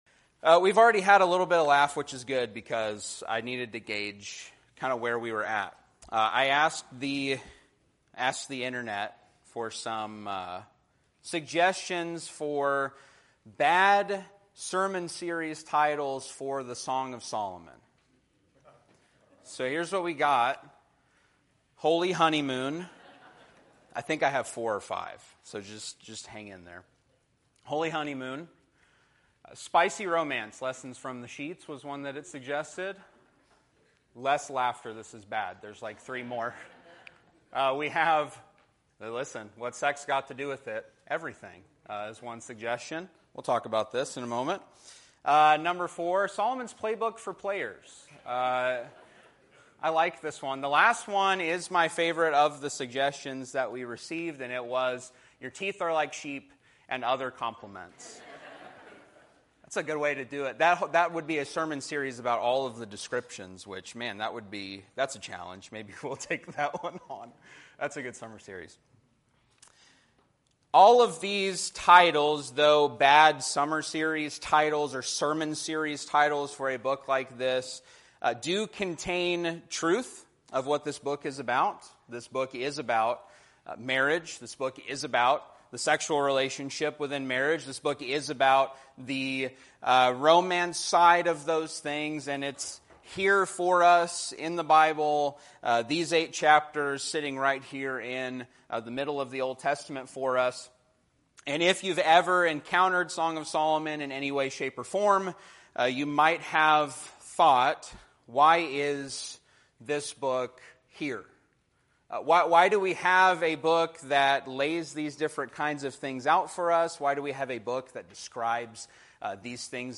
We opened with a few laughs, sharing tongue-in-cheek “bad sermon series titles” for the Song of Solomon—like Holy Honeymoon and Your Teeth Are Like Sheep and Other Compliments.